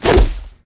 grenade throw.ogg